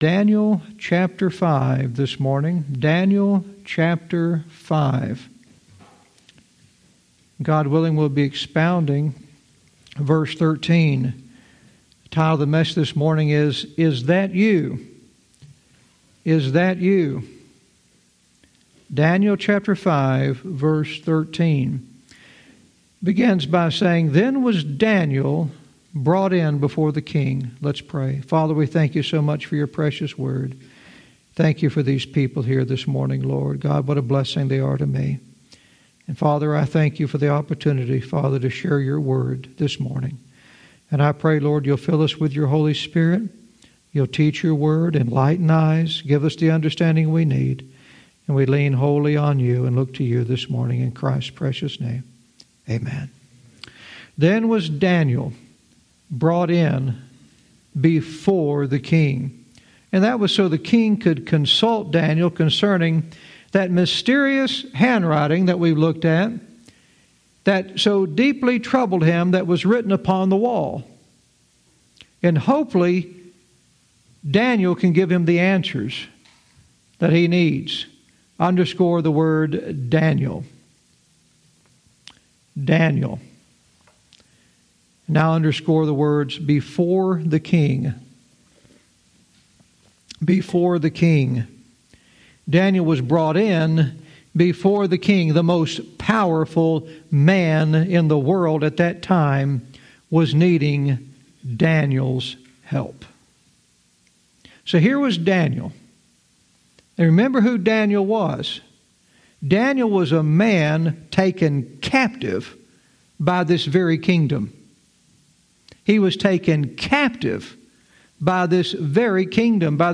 Verse by verse teaching - Daniel 5:13 "Is That You?"